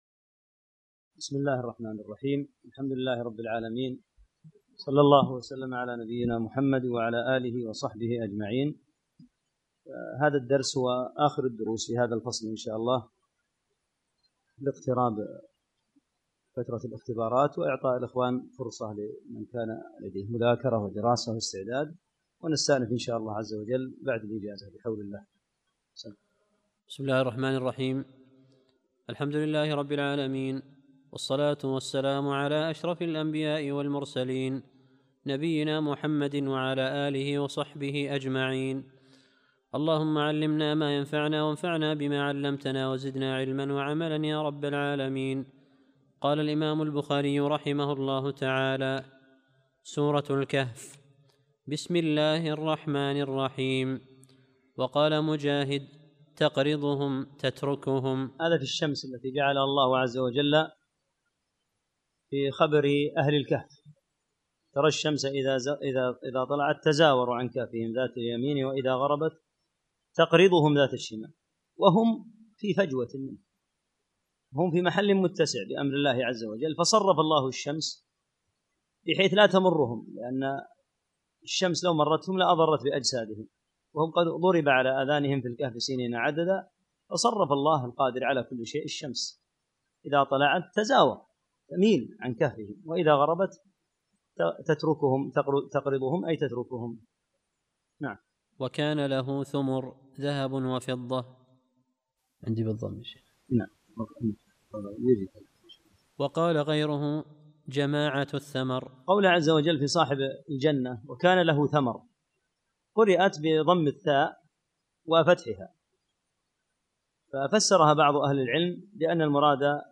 30- الدرس الثلاثون